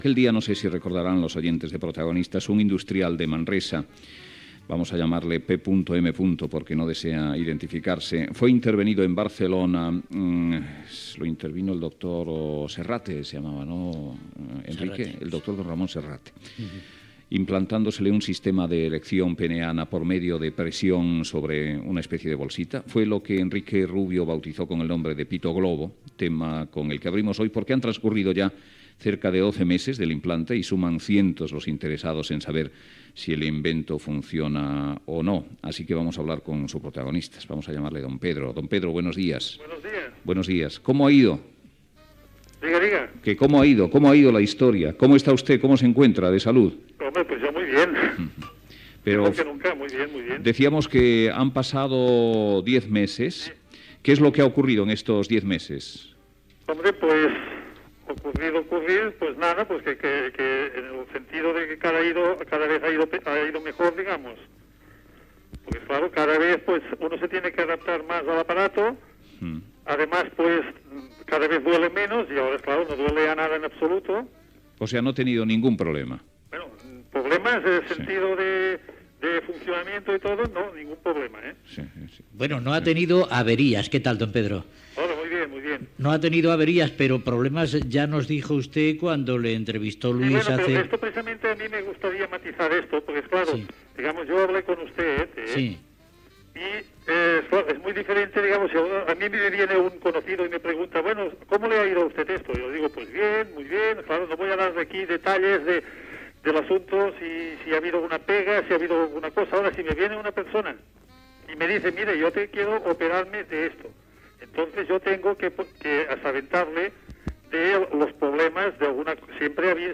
Trucada a un pacient implantat de pròtesi inflable del penis, un any després de l'operació.
Info-entreteniment